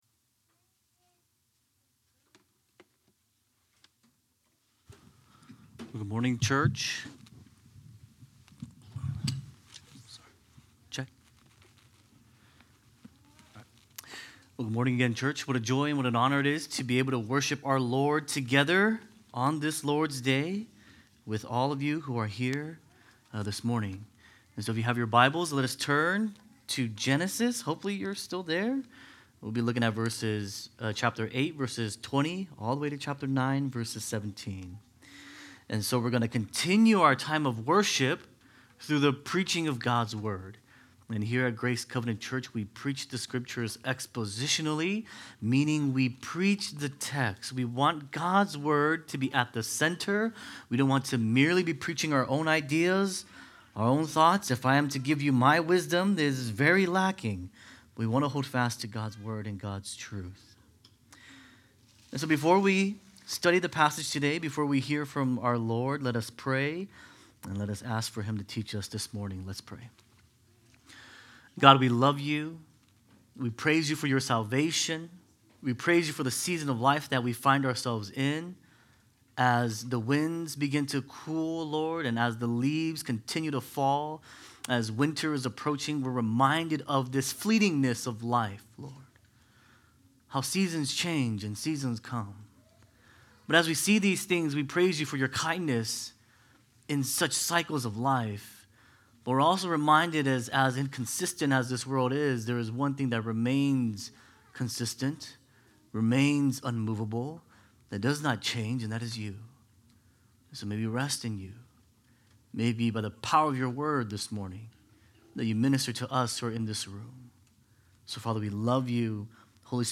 Sermons | Grace Covenant Church